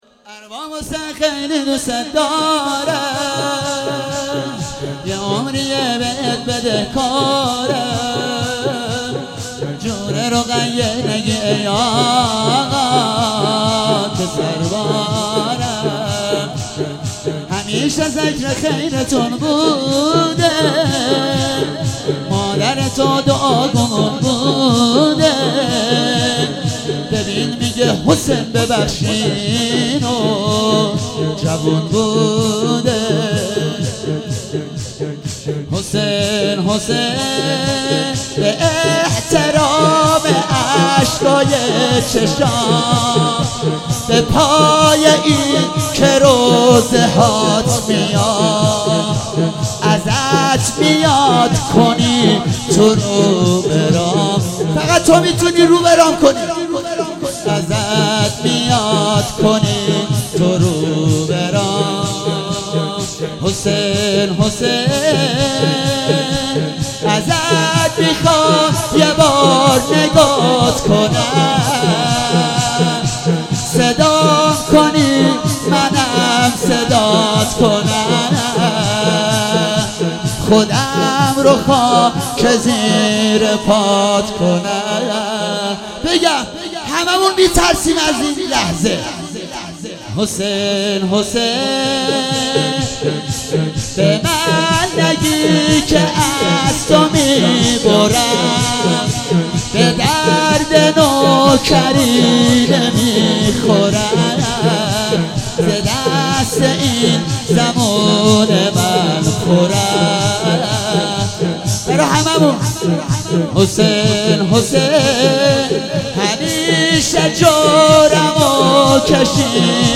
شور - ارباب حسین خیلی دوست دارم